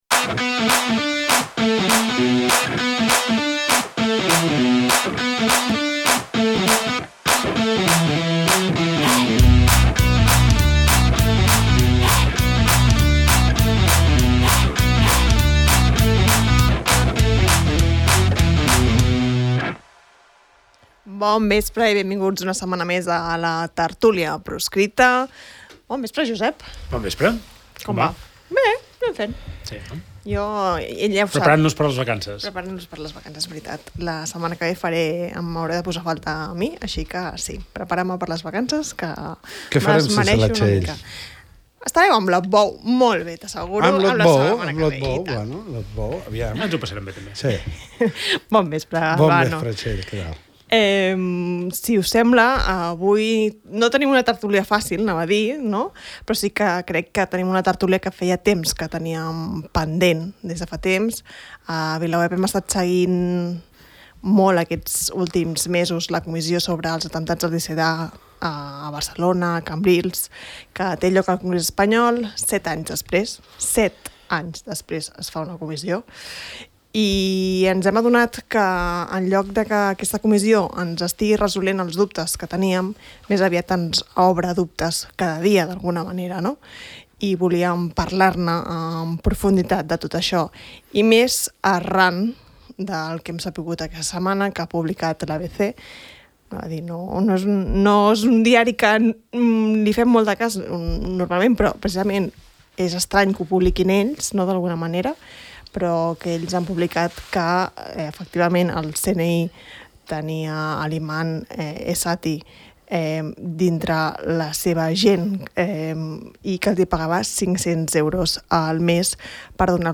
un debat de VilaWeb sobre l’actualitat política